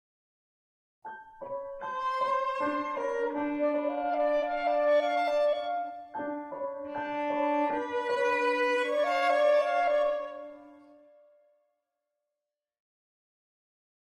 (1999) for clarinet, violin, cello, and piano. 3 minutes.